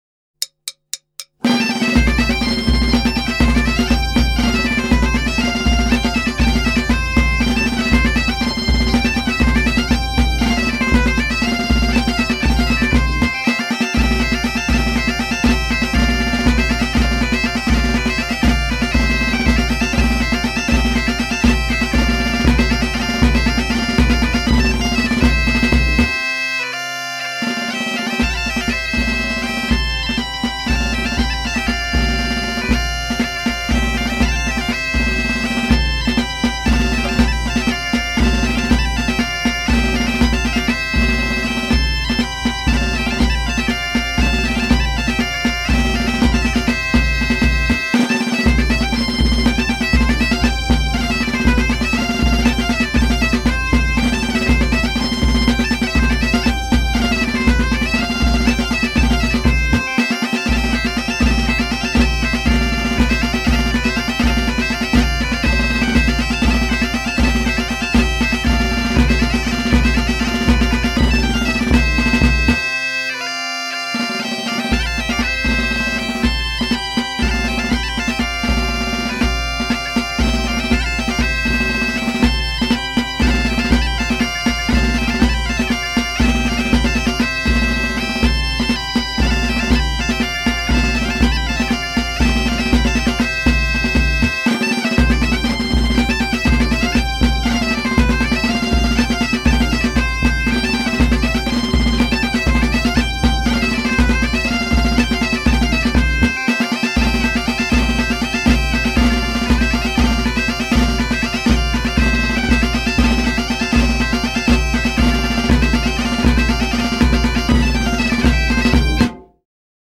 arranxadas para cuarteto tradicional
dúas gaitas, tambor e bombo